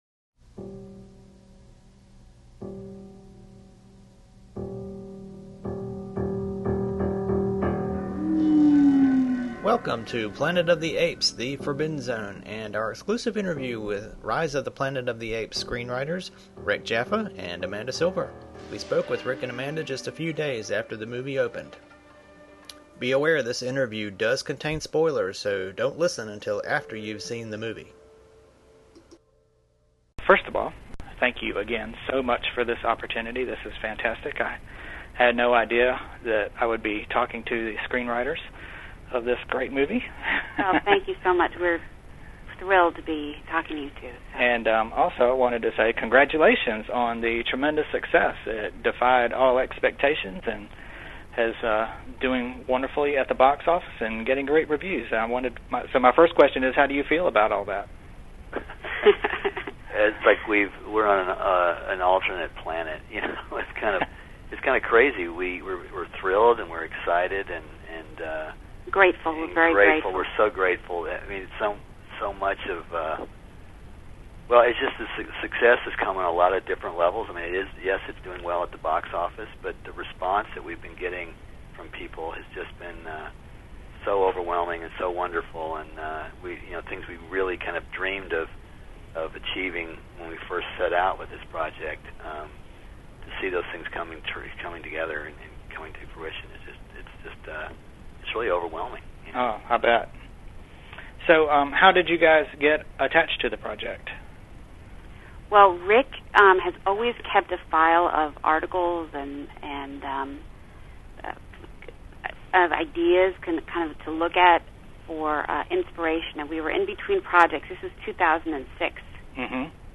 Forbidden Zone Exclusive! Interview with Rise of the Planet of the Apes Screenwriters Rick Jaffa and Amanda Silver
jaffa_silver_interview.mp3